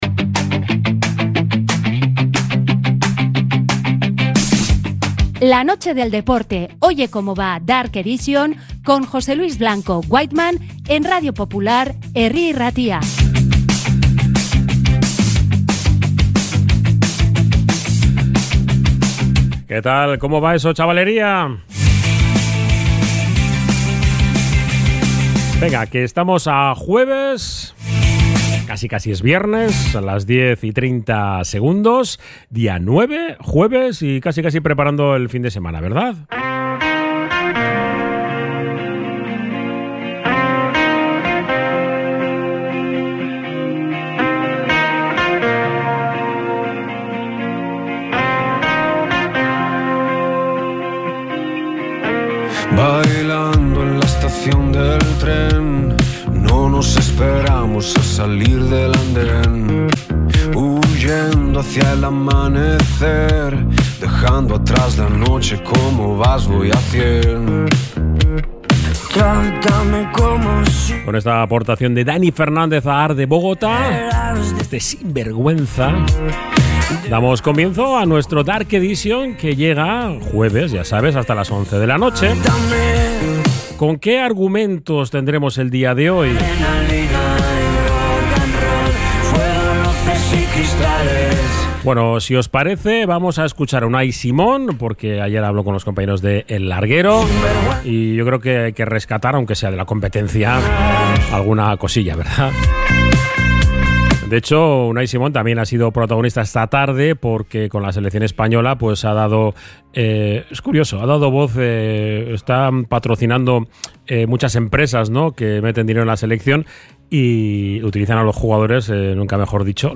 Oye Como Va Dark Edition 09.10.2025 Entrevista a Boiro, Asdefebi, basket...